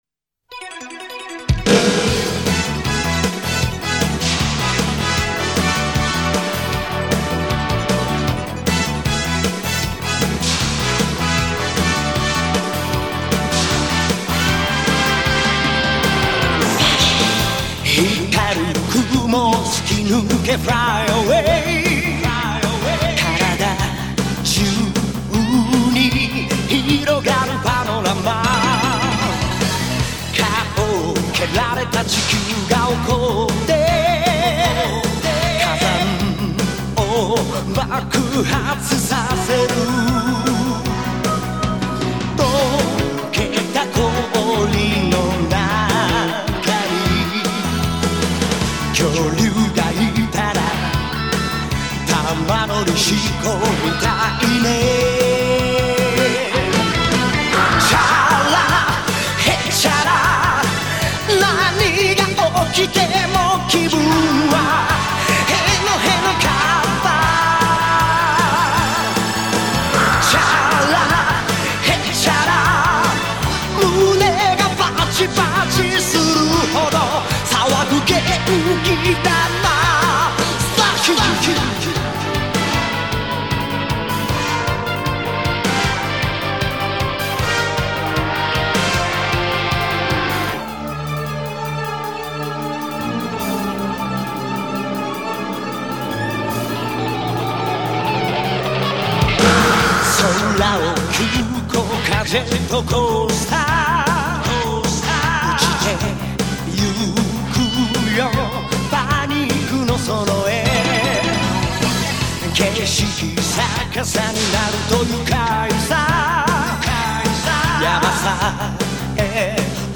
Japanese theme song